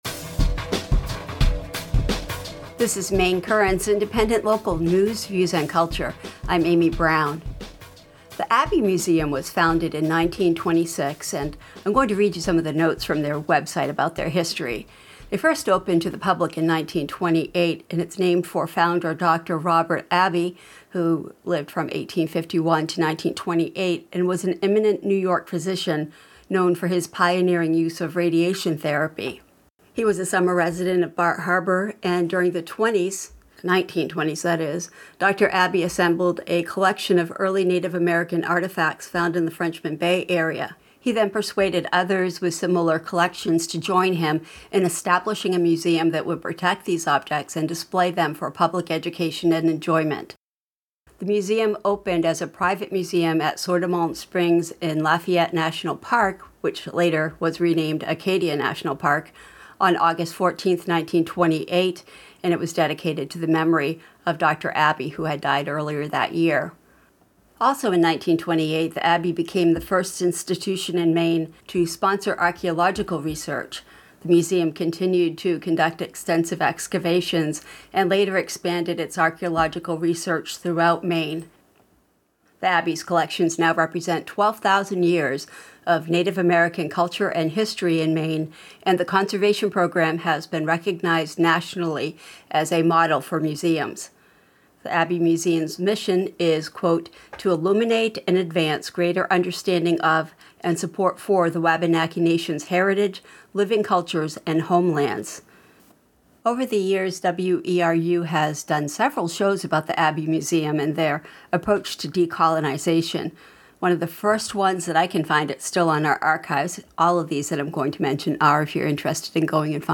In this case to a Canadian Studies class at Harvard where he interviewed Justice Rosalie Abella about her early life